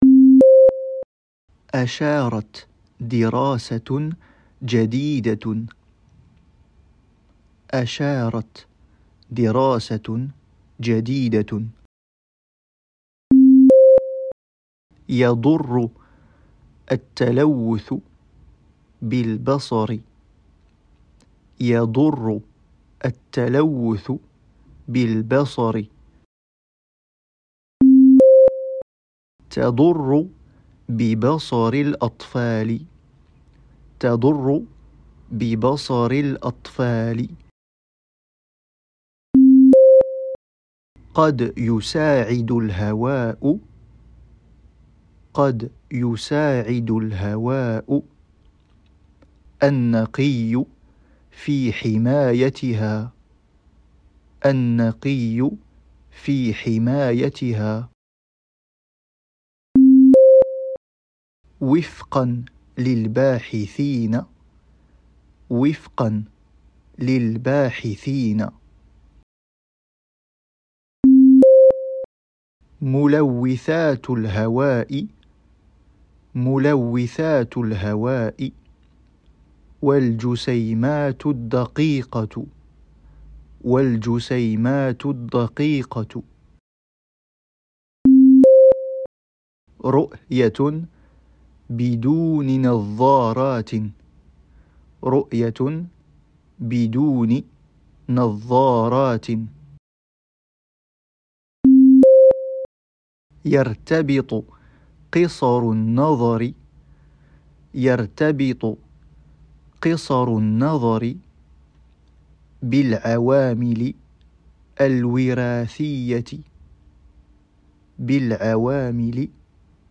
Dictée